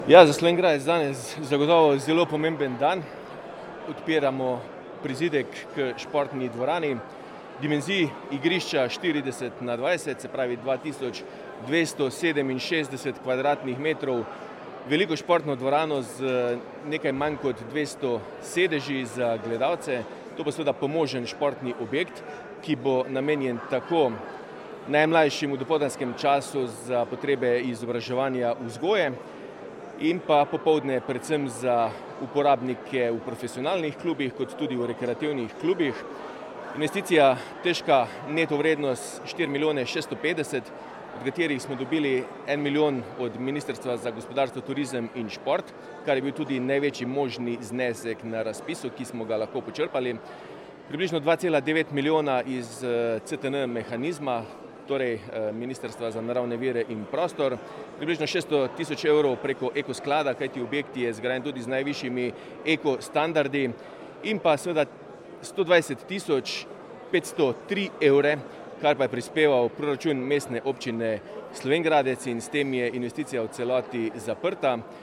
Župan Mestne občine Slovenj Gradec Tilen Klugler: